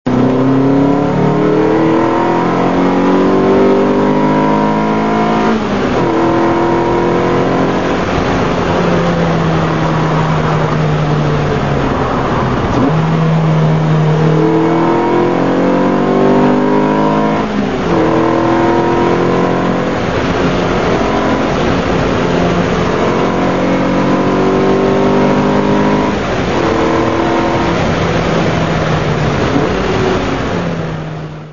Lotus Elise II with K&N air filter (MP3 123Ko)